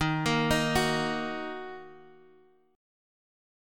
Ebm Chord